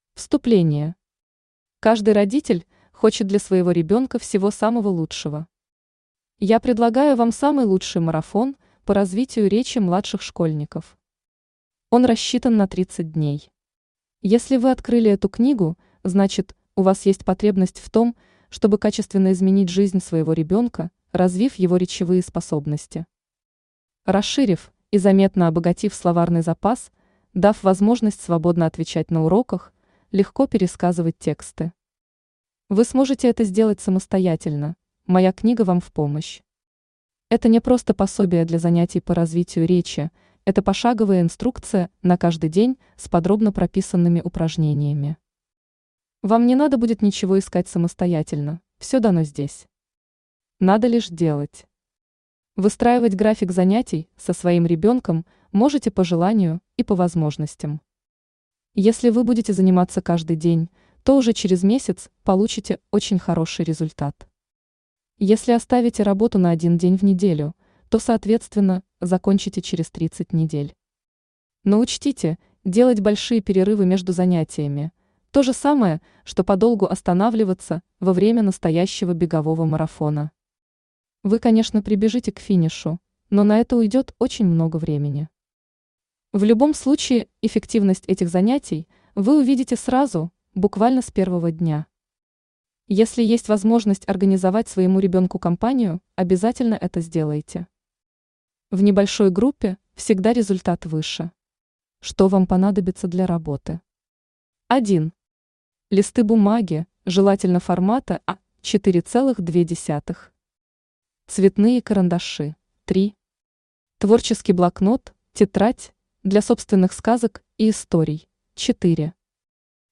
Аудиокнига 30-дневный марафон по развитию речи вашего ребёнка | Библиотека аудиокниг